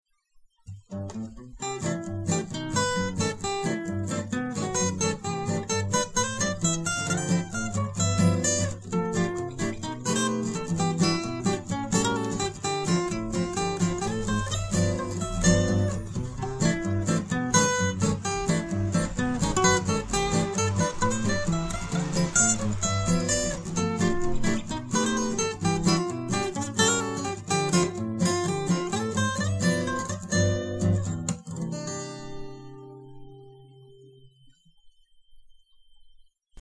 Guitar arrangements